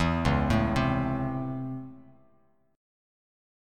C#mM7#5 chord